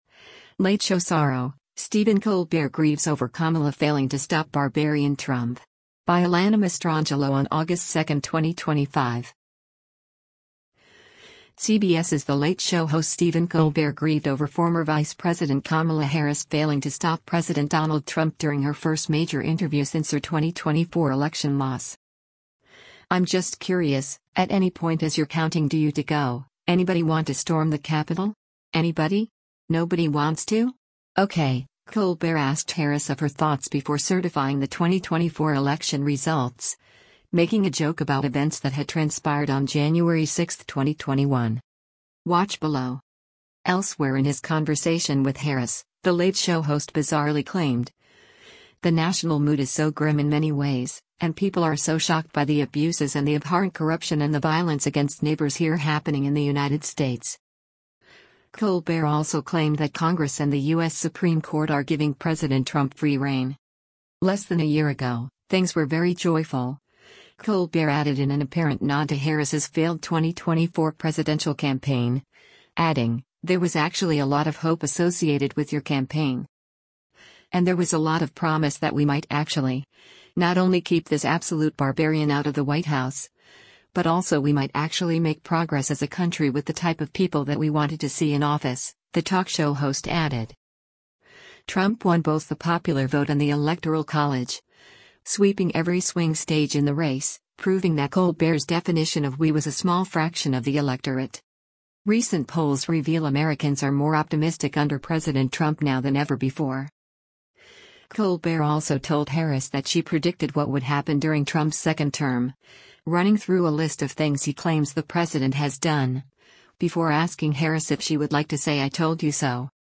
The Late Show with Stephen Colbert and guest Vice President Kamala Harris during Thursday'
CBS’s The Late Show host Stephen Colbert grieved over former Vice President Kamala Harris failing to stop President Donald Trump during her first major interview since her 2024 election loss.